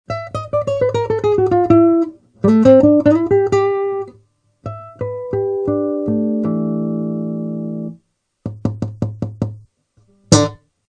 A seguir são disponibilizados alguns pequenos arquivos MP3 demonstrando a sonoridade original e a sonoridade após a equalização.
Violão sem Caixa de Ressonância
Efeitos (com eq.)